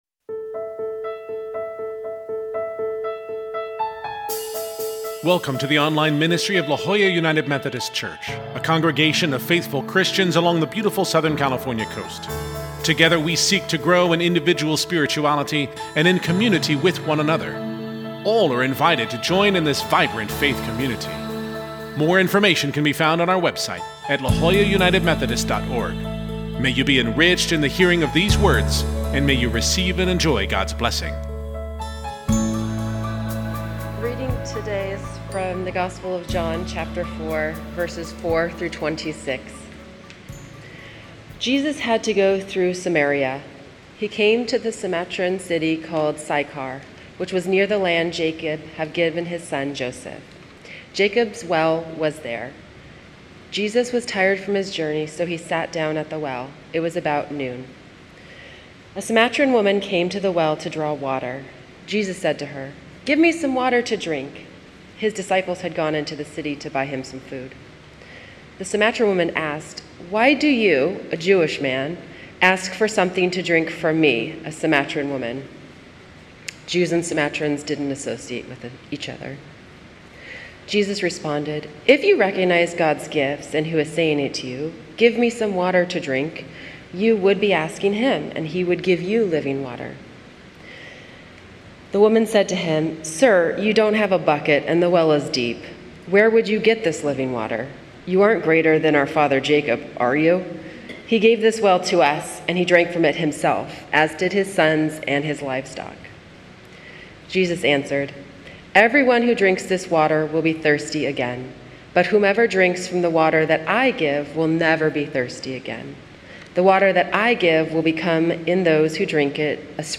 Scripture: John 4:4-26 (CEB) worship bulletin Sermon Note Share this: Print (Opens in new window) Print Share on X (Opens in new window) X Share on Facebook (Opens in new window) Facebook